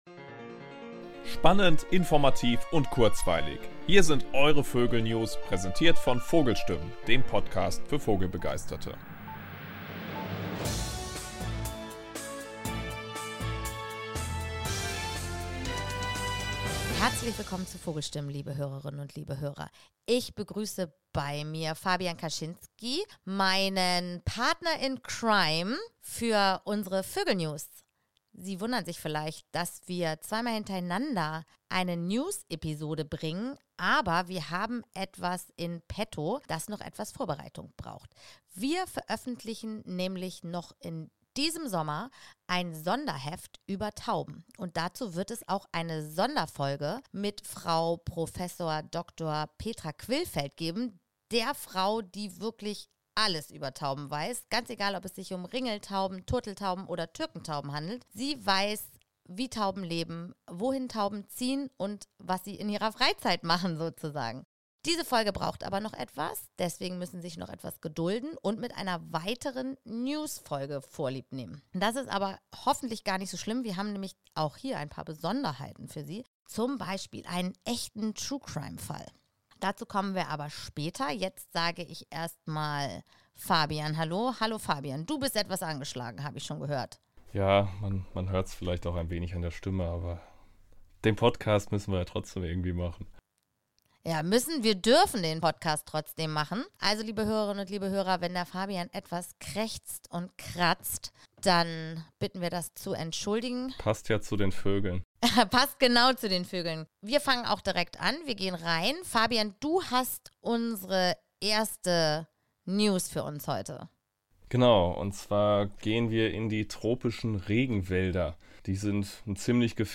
Neben interessanten Gesprächen mit Autorinnen und Autoren des Magazins, gibt es zwischen den heftbegleitenden Episoden unsere VÖGEL NEWS.